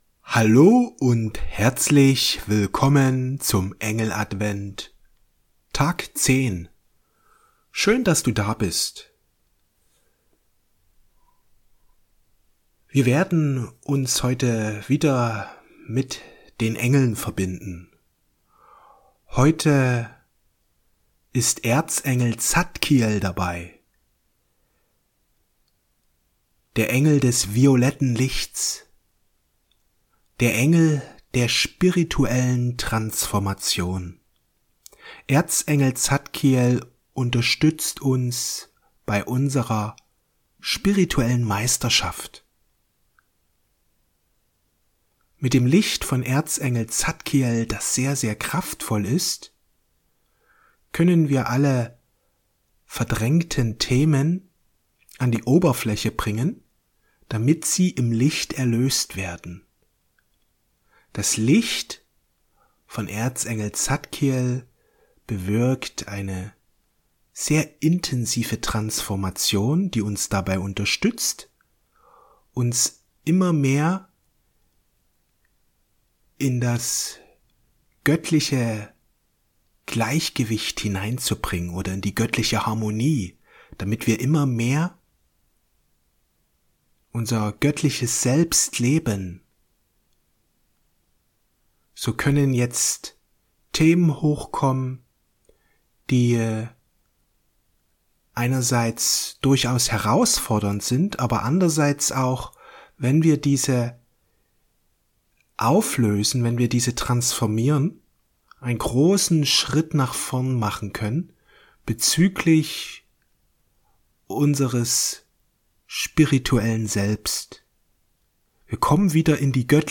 Das violette Licht der spirituellen Transformation Meditation mit Erzengel Zadkiel